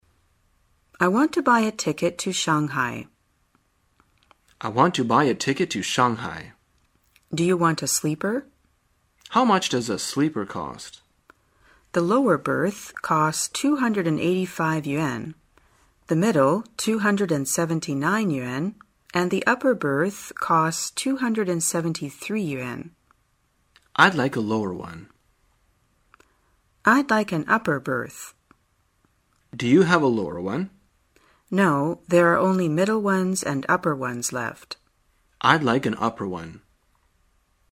在线英语听力室生活口语天天说 第95期:怎样选购车票的听力文件下载,《生活口语天天说》栏目将日常生活中最常用到的口语句型进行收集和重点讲解。真人发音配字幕帮助英语爱好者们练习听力并进行口语跟读。